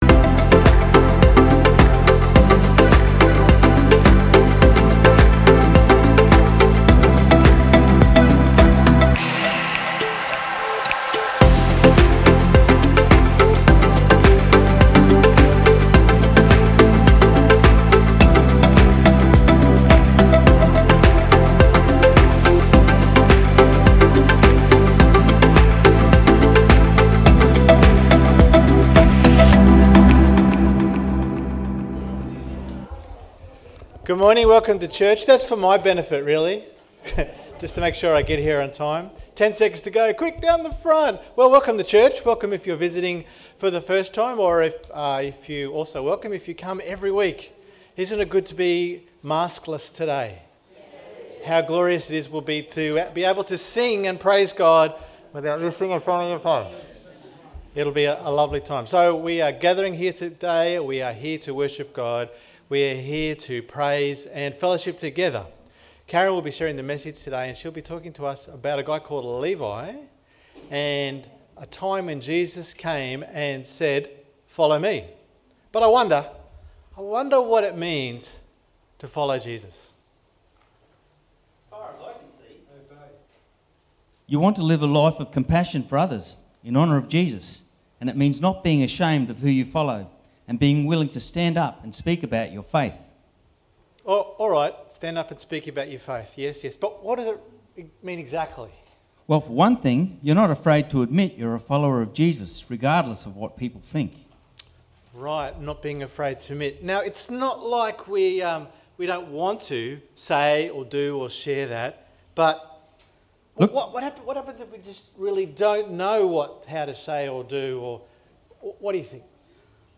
Second Week of the Defiance series "A Sinners Party" Sorry for the quality due to some technical issues